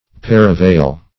Paravail \Par`a*vail"\, a. [OF. par aval below; par through (L.